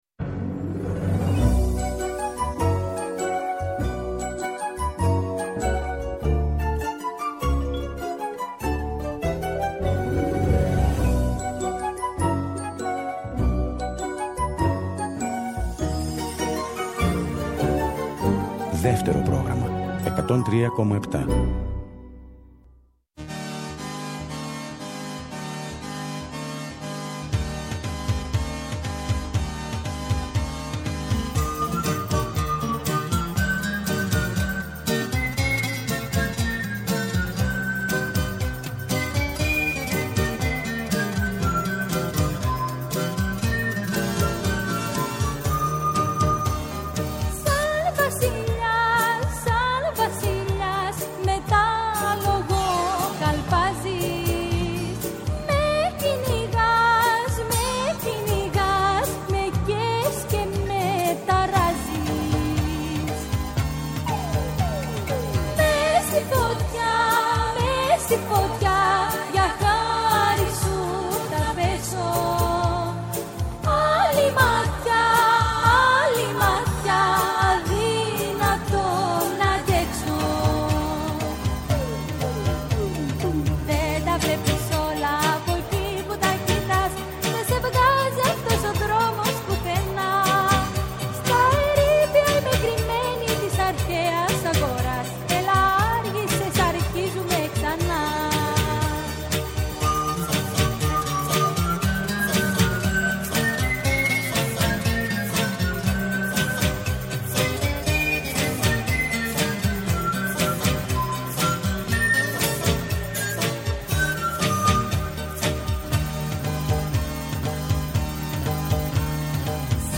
Τα τραγούδια της παρέας και πάντα το καινούργιο τραγούδι της ημέρας! Παλιά τραγούδια που κουβαλάνε μνήμες αλλά και νέα που πρόκειται να μας συντροφεύσουν.